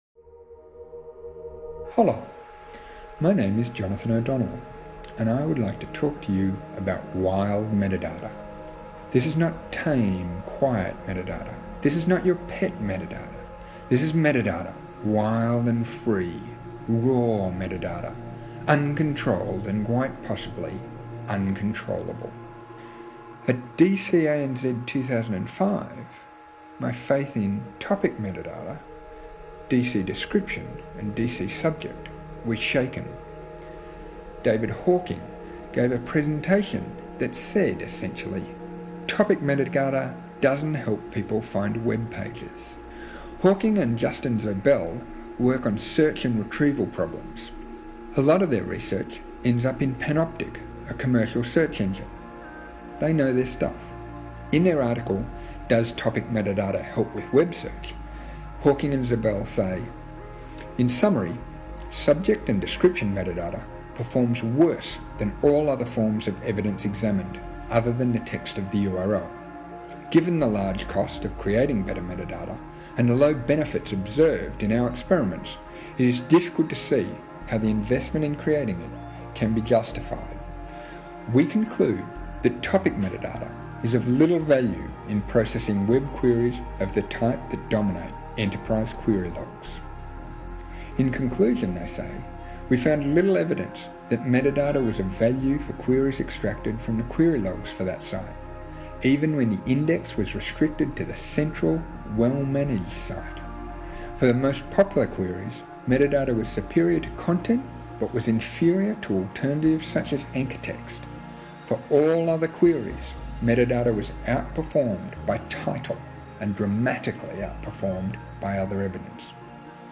A podcast outlining this idea is available: Wild Metadata: presentation for DC 2006.